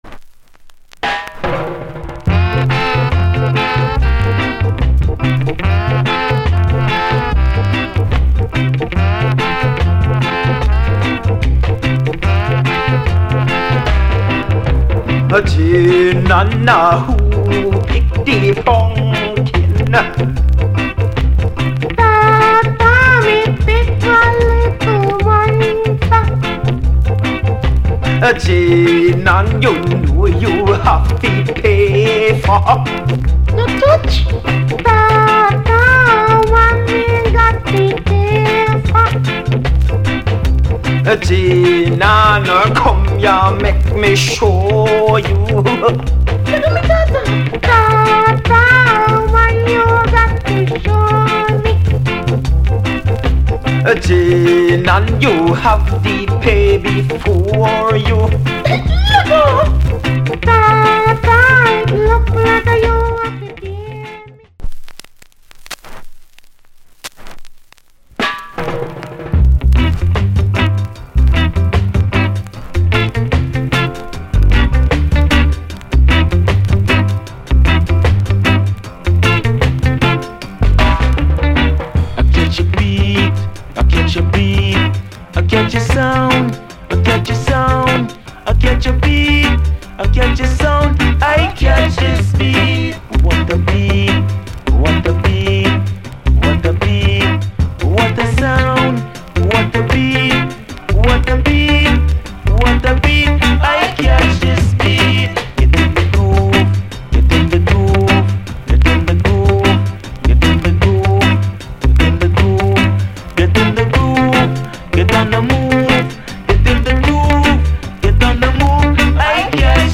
* ジャマイカン・メントをスキンズ・スタイルでリメイク。カリブの匂いがしてくる様なアップテンポでイナタイ渚系初期レゲエ。